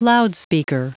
Prononciation du mot loudspeaker en anglais (fichier audio)
Prononciation du mot : loudspeaker